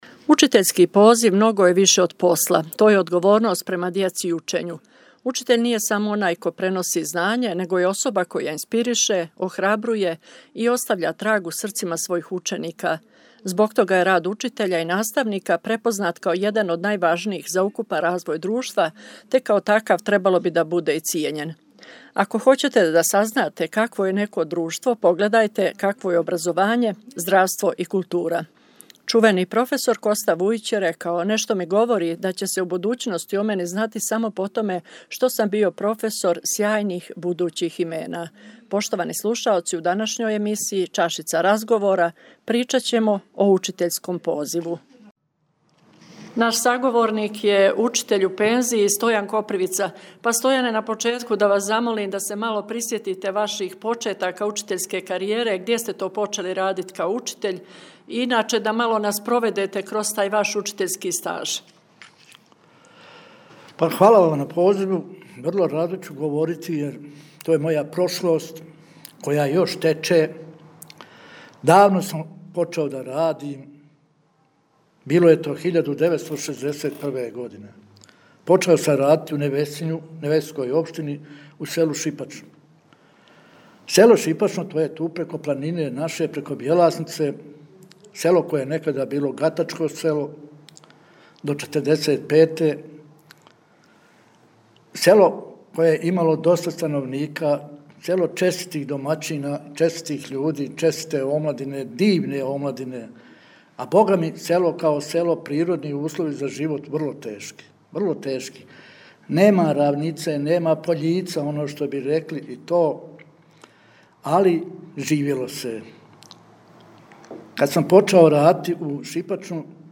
Носталгично и сјетно
са тугом у гласу